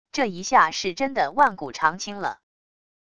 这一下是真的万古长青了wav音频生成系统WAV Audio Player